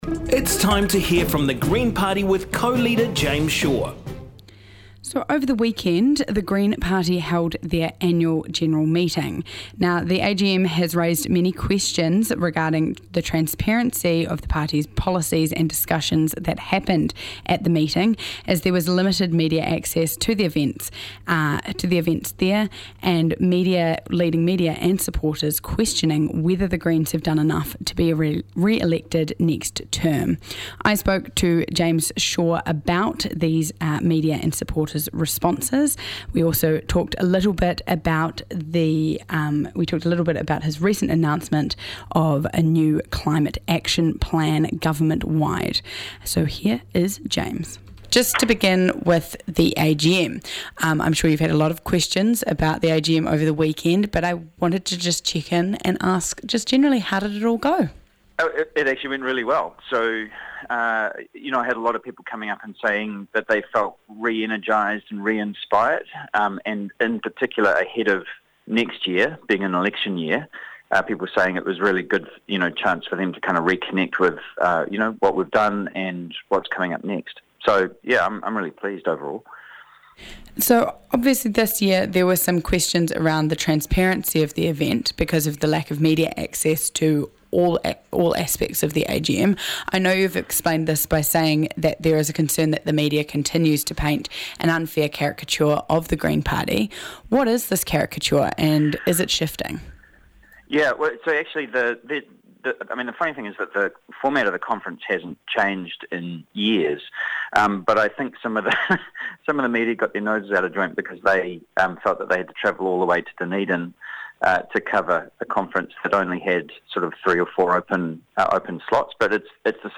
spoke to co-leader James Shaw about these concerns.